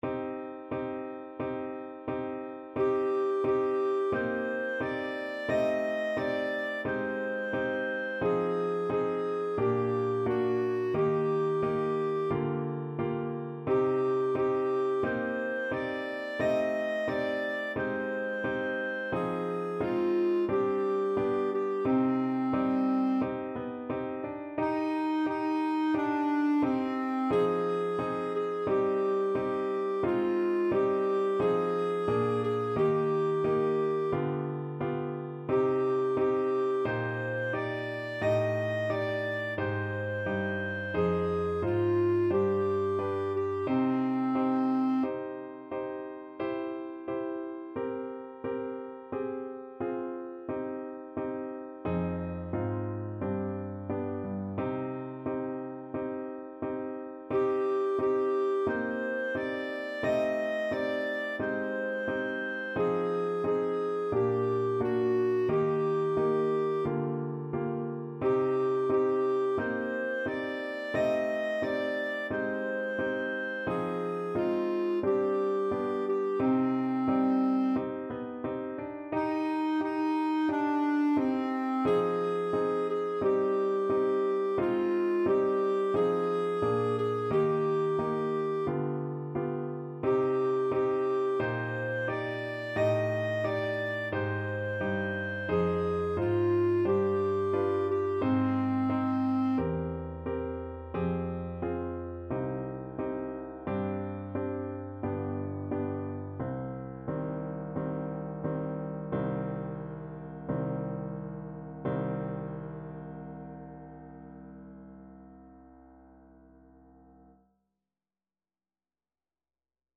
Classical Trad. Kojo no Tsuki Clarinet version
C minor (Sounding Pitch) D minor (Clarinet in Bb) (View more C minor Music for Clarinet )
4/4 (View more 4/4 Music)
Andante =c.88
Classical (View more Classical Clarinet Music)
Japanese